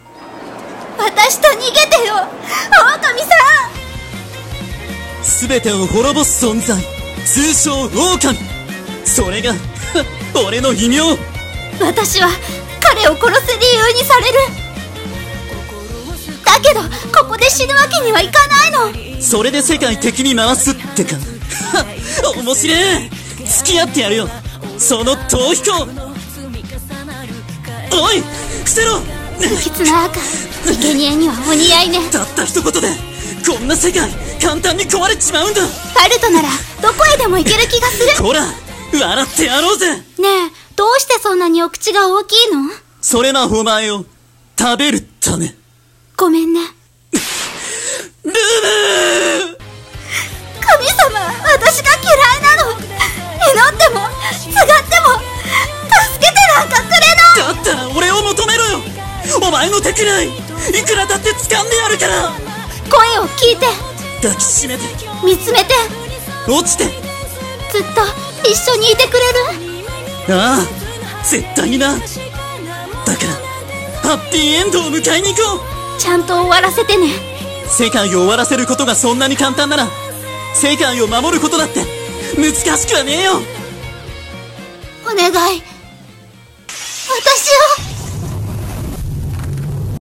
【予告風声劇】